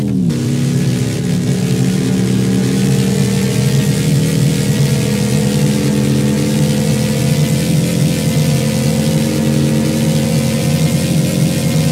Index of /server/sound/vehicles/lwcars/morgan_3wheeler